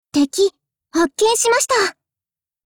Cv-20227_warcry.mp3